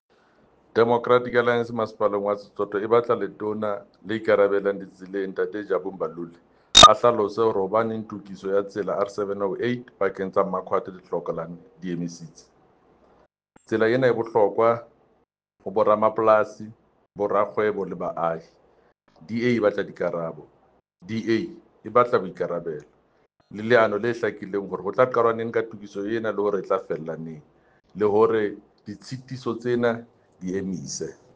Sesotho soundbite by Jafta Mokoena MPL.